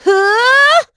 Estelle-Vox_Casting3.wav